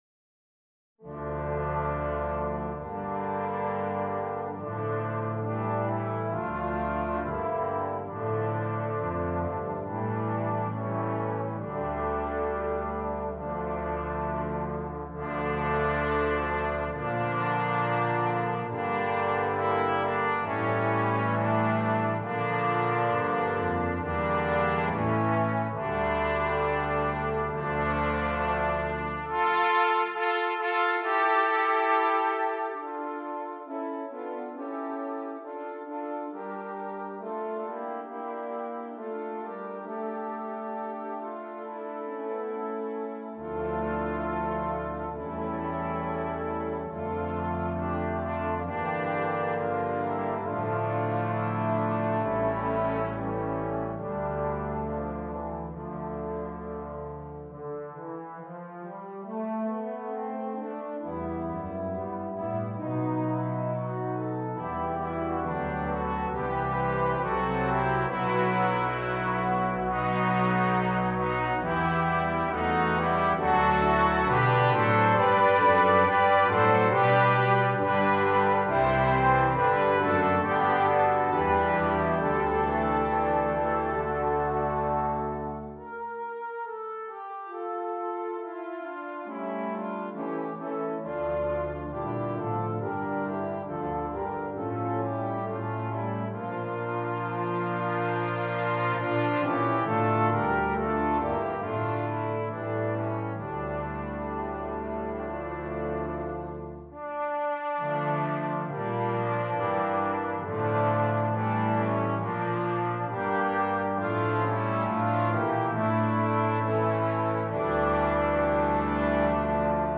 Brass Choir (2.2.2.0.1)